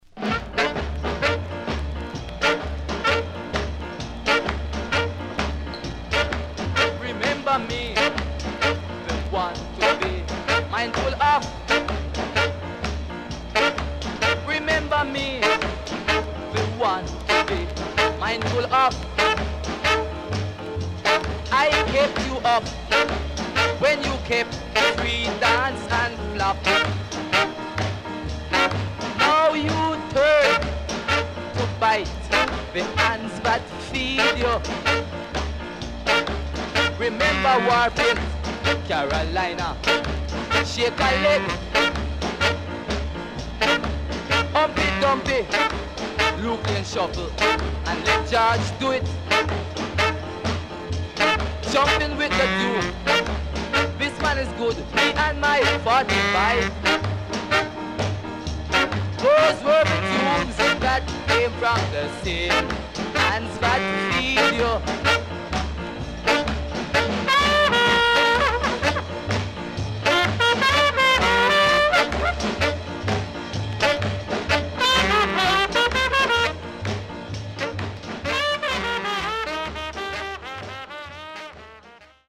SIDE A:所々チリノイズ入ります。